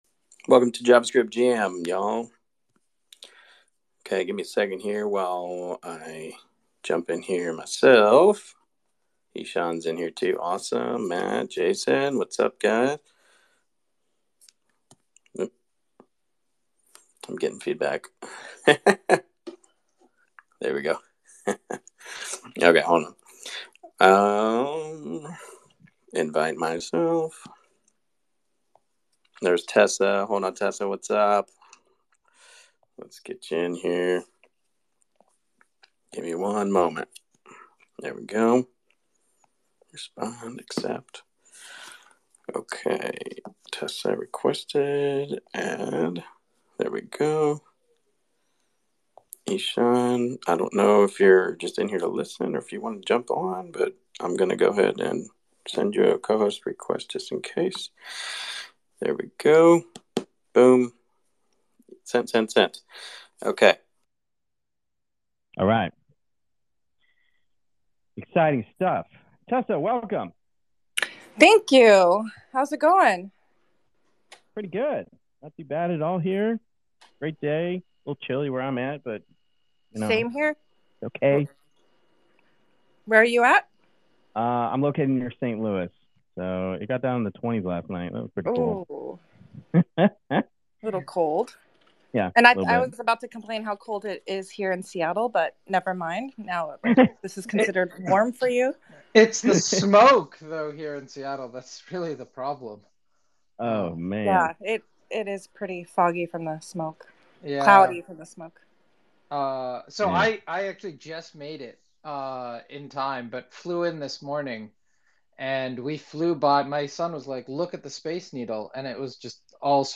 Chapters 00:00 - 02:55 Introduction and Beginning of Episode This opening segment sets the stage by welcoming listeners to another edition of JavaScript Jam, a weekly live show geared toward developers of all levels.
The episode’s laid-back, inclusive atmosphere quickly becomes apparent, as new attendees are warmly greeted and technical topics are casually teased.